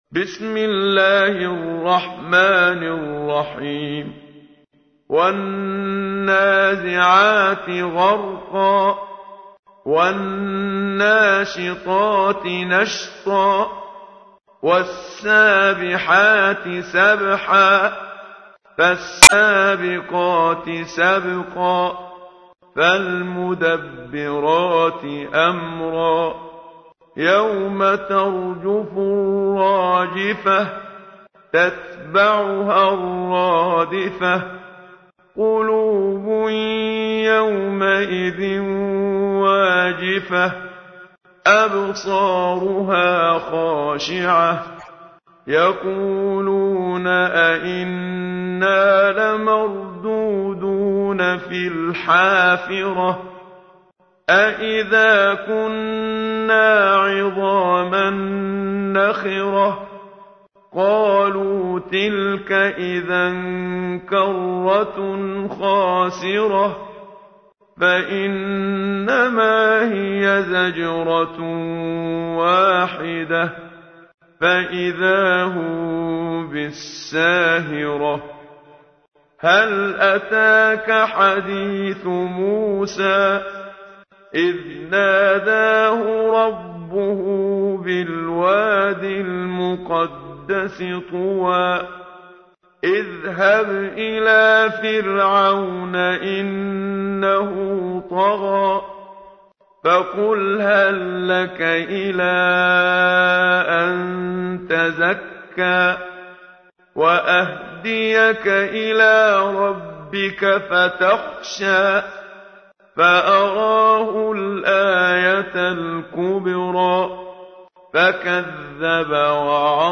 تحميل : 79. سورة النازعات / القارئ محمد صديق المنشاوي / القرآن الكريم / موقع يا حسين